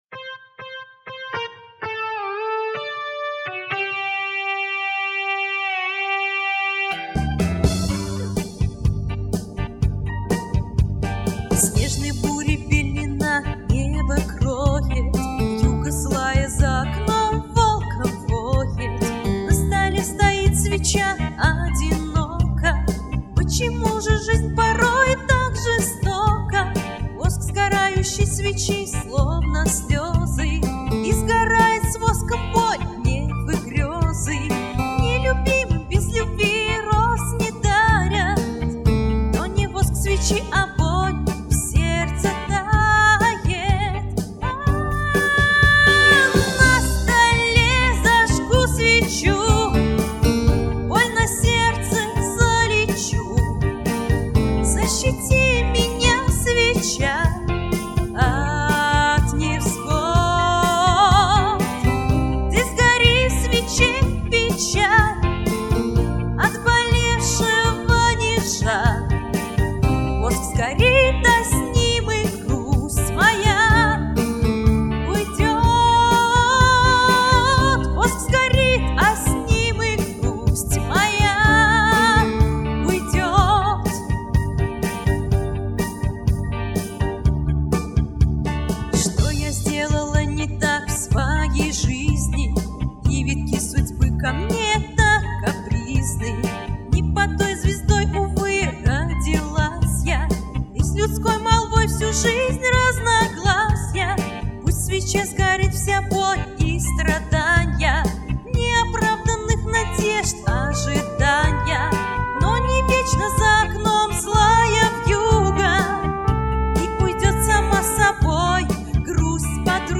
Русский поп-шансон